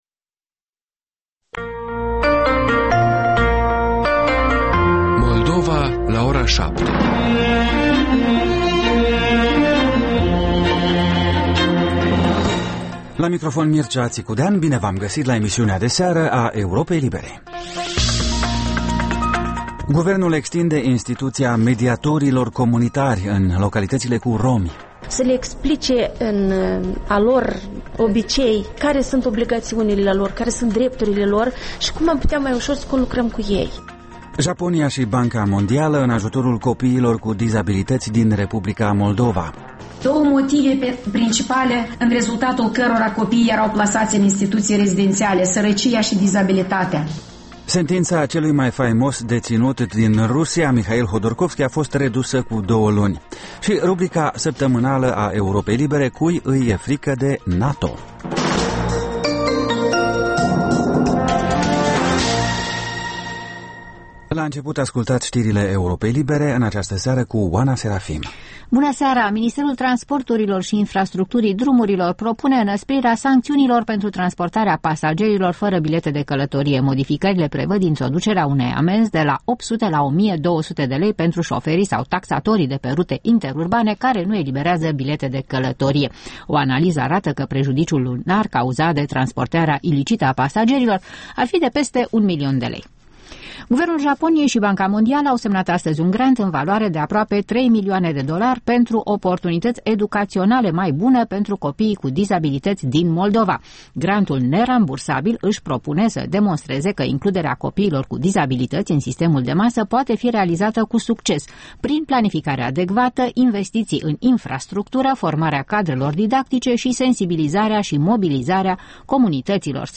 Ştiri, interviuri, analize şi comentarii. În fiecare marţi, Neculai Constantin Munteanu comentează realitatea românească.